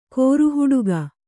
♪ kōru huḍuga